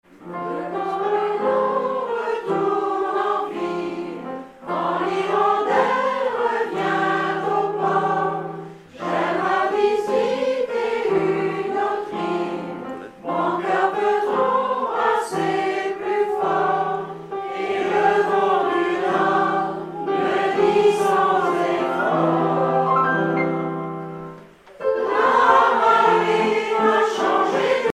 Informateur(s) Chorale de Miquelon association
repiquage d'une cassette audio
Pièce musicale inédite